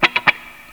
RIFFGTRLP2-R.wav